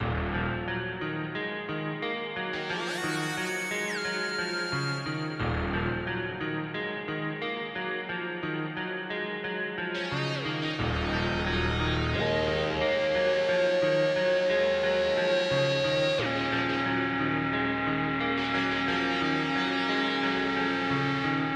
描述：小类型软性嘻哈摇滚乐循环
Tag: 89 bpm Hip Hop Loops Guitar Electric Loops 3.63 MB wav Key : Unknown